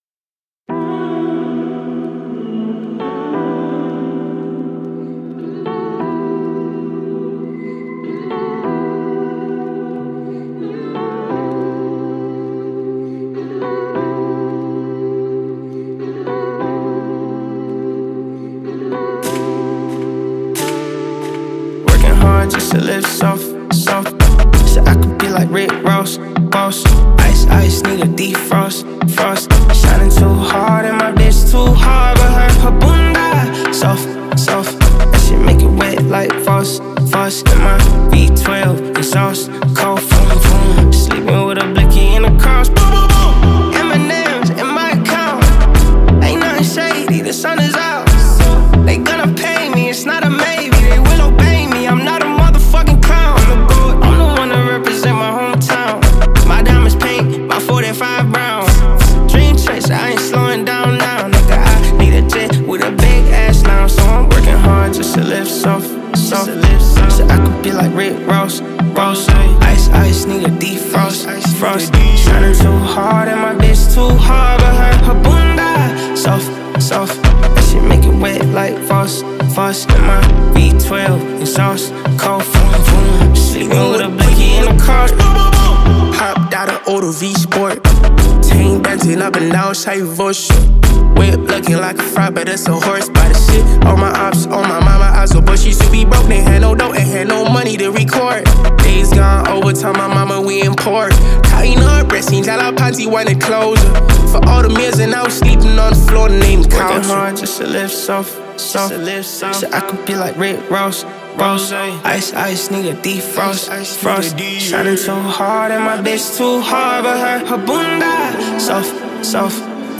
Talented rapper and songwriter